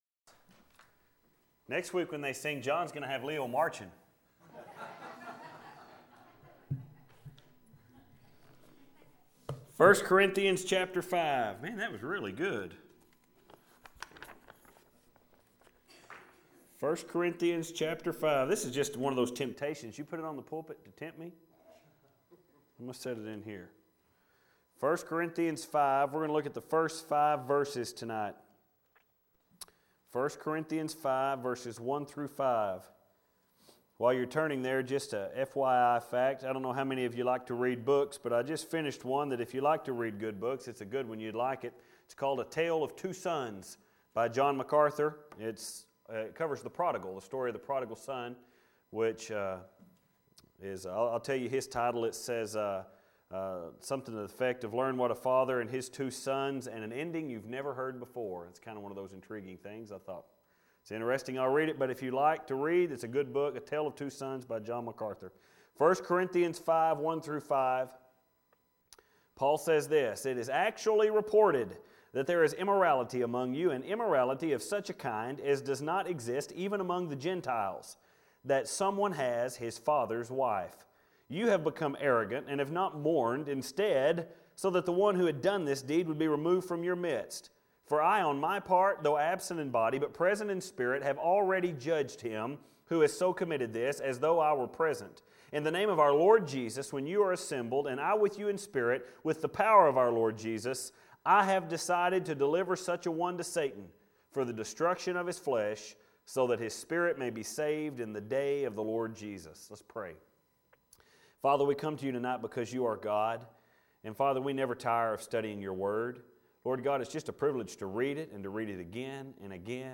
Filed Under: Sermons Tagged With: Corinthians